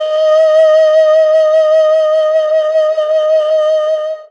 52-bi17-erhu-p-d#4.wav